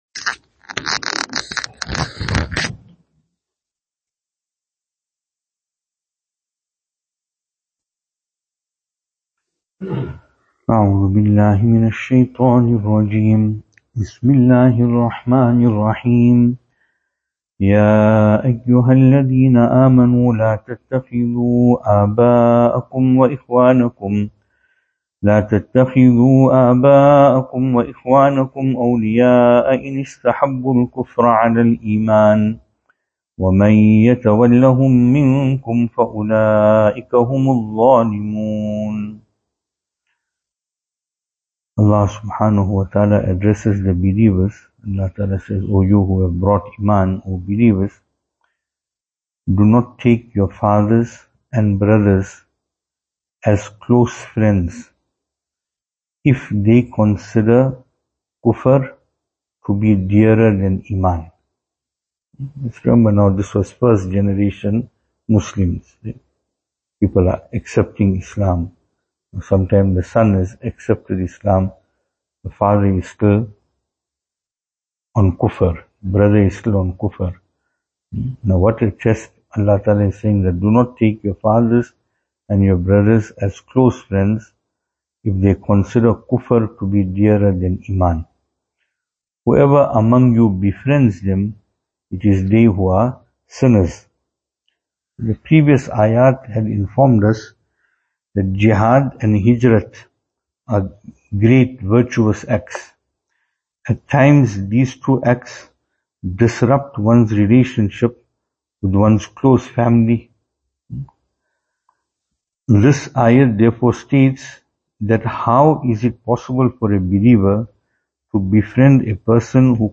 2025-03-25 Programme After Fajr Venue: Albert Falls , Madressa Isha'atul Haq Series